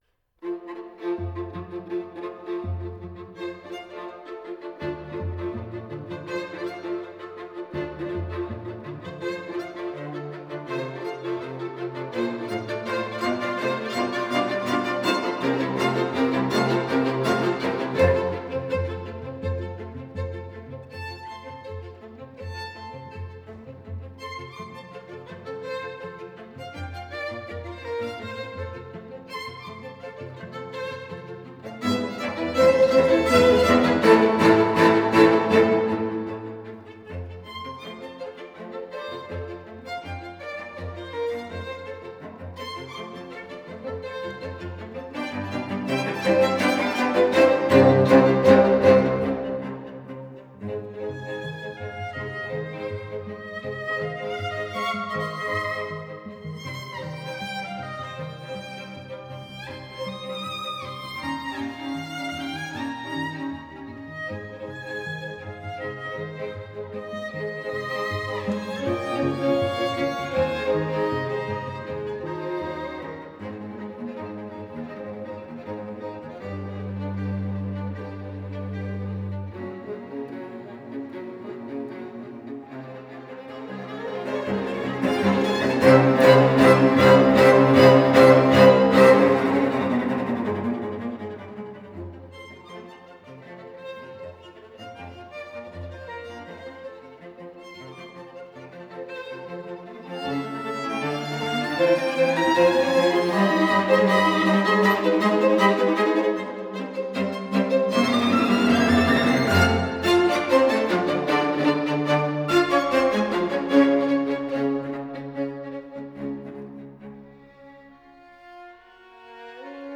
Strijkkwartet fragmenten